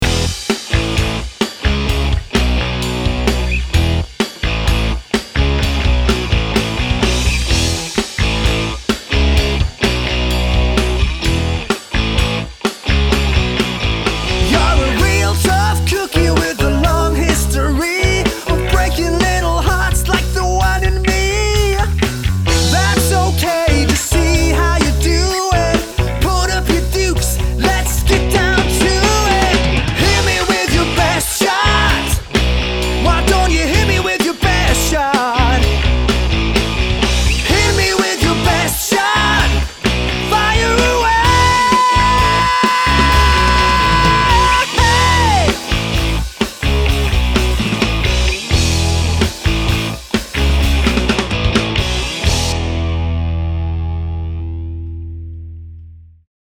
Fantastic 4 Live Piece Party Band